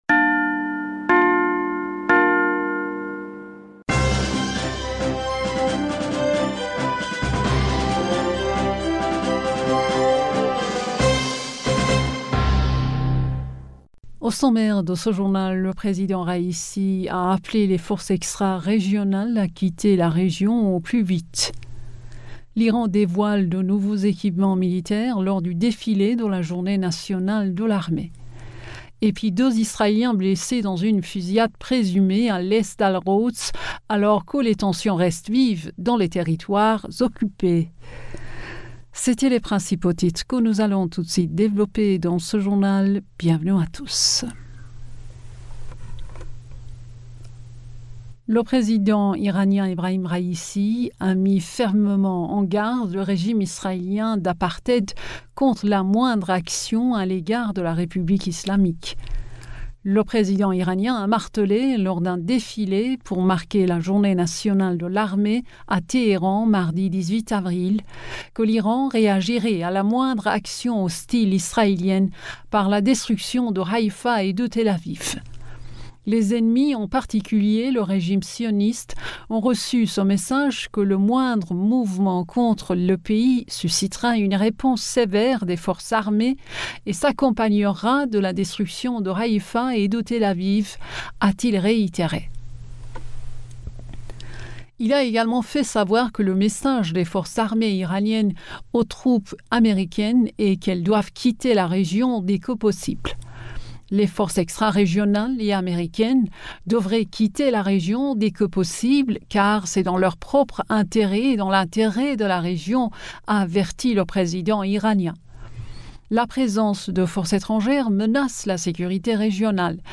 Bulletin d'information du 18 Avril 2023